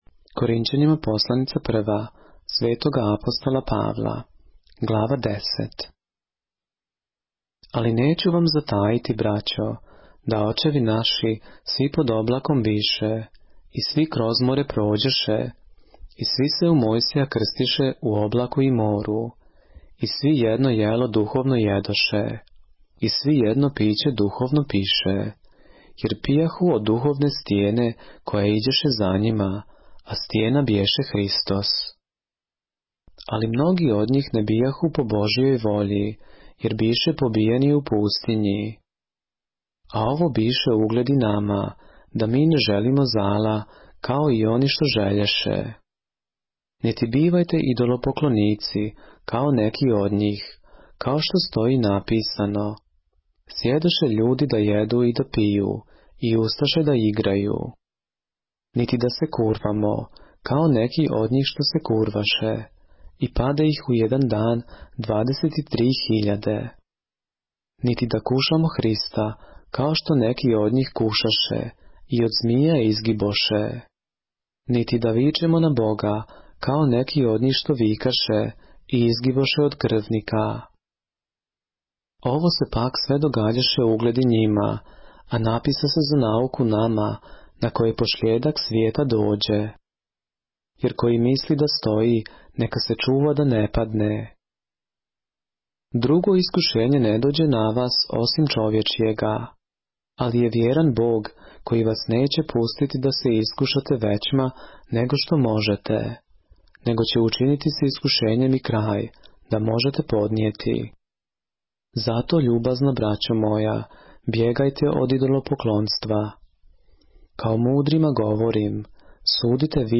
поглавље српске Библије - са аудио нарације - 1 Corinthians, chapter 10 of the Holy Bible in the Serbian language